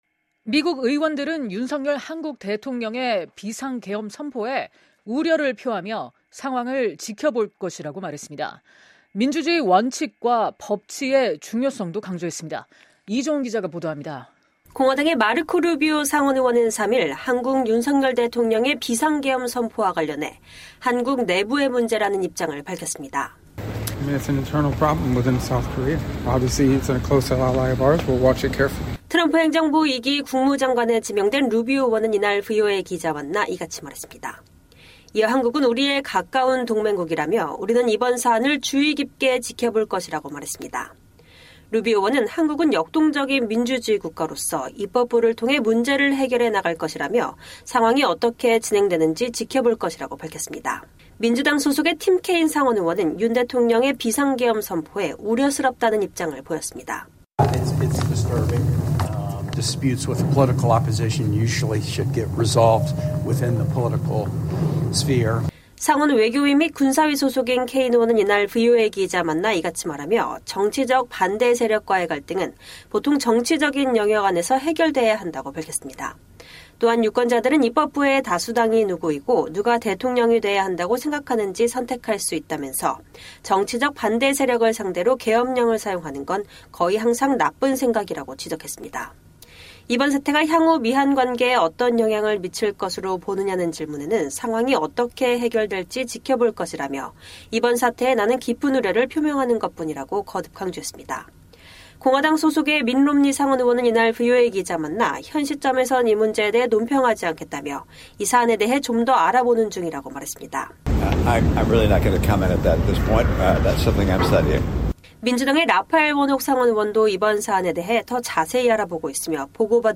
트럼프 행정부 2기 국무장관에 지명된 루비오 의원은 이날 VOA 기자와 만나 이같이 말했습니다.